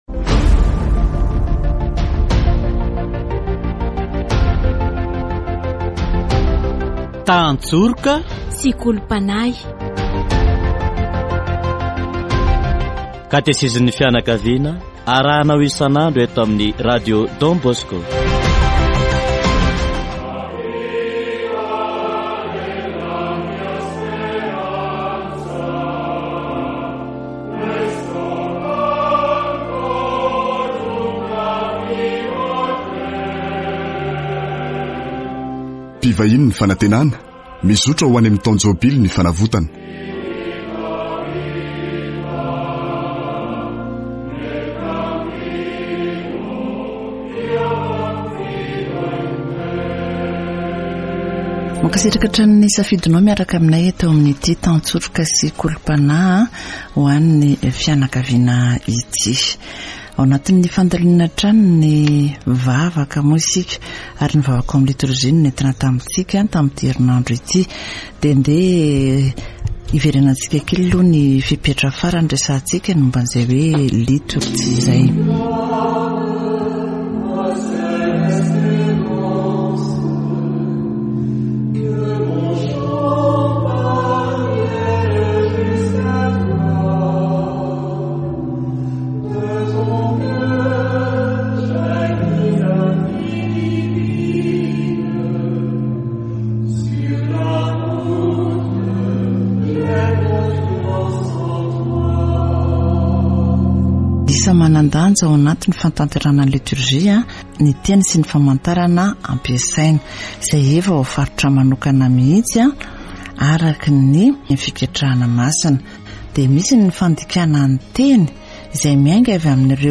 Katesizy momba ny vavaka ao amin'ny litorjia